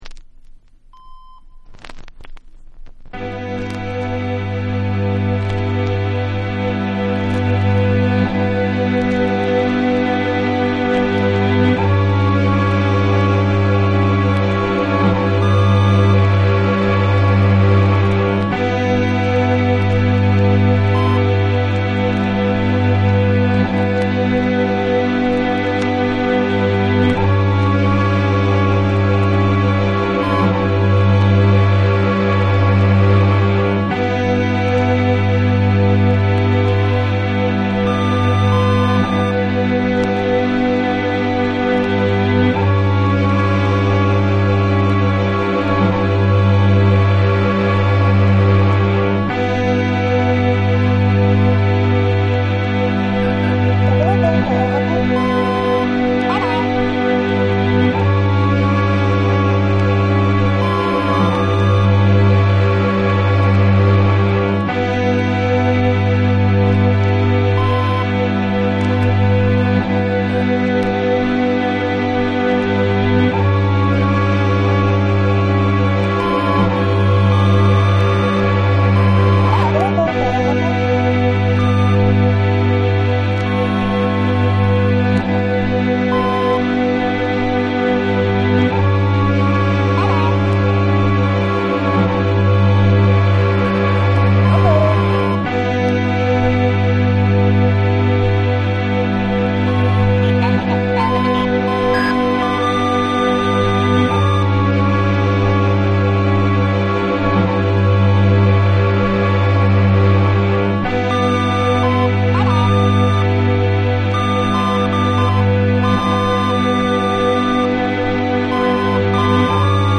UK FUNKY
BREAKBEATS